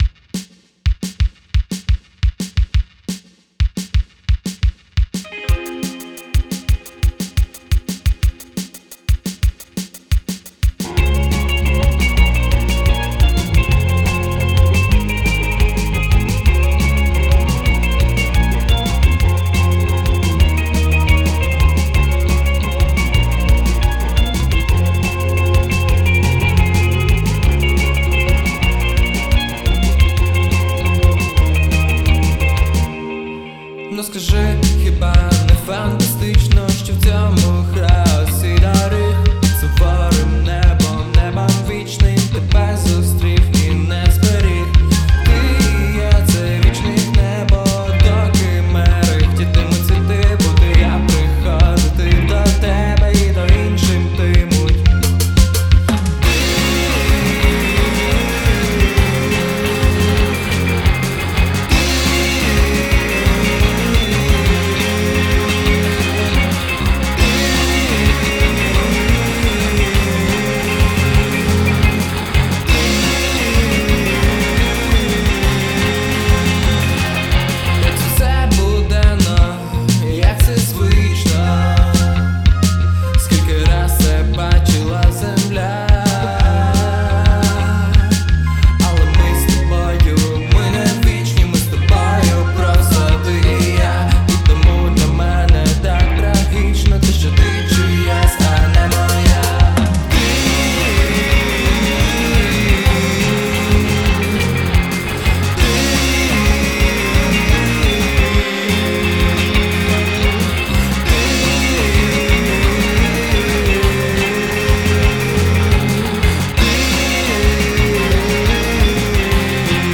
• Жанр: Rock